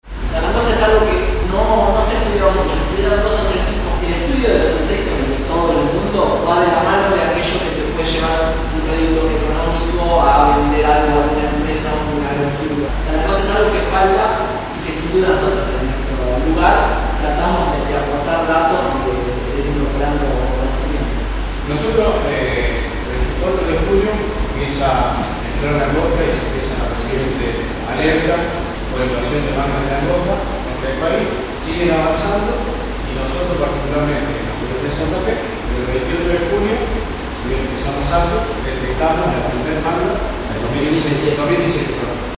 Este martes a la noche, Villa Minetti fue escenario de una charla organizada por Senasa, que tuvo como fin informar acerca de la situación de la langosta en Santa Fe y el monitoreo y control que se está haciendo en distintos sectores.